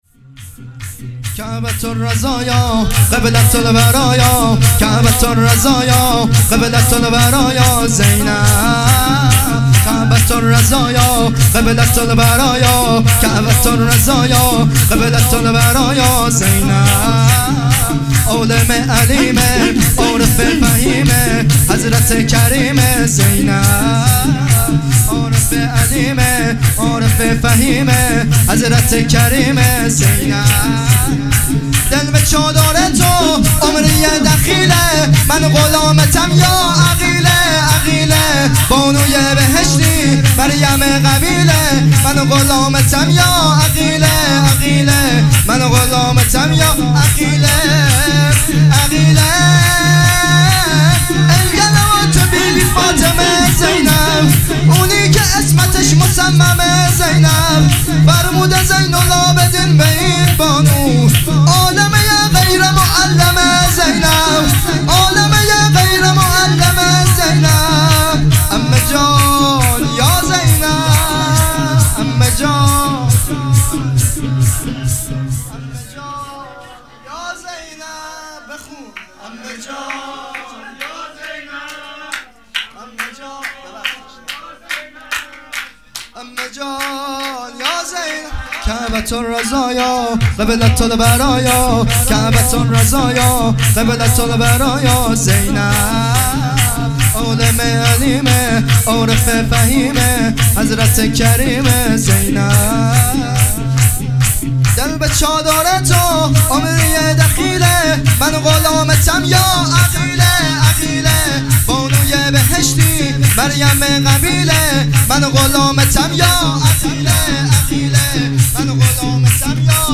جشن ولادت حضرت زینب سلام الله ۱۲-۱۰-۹۸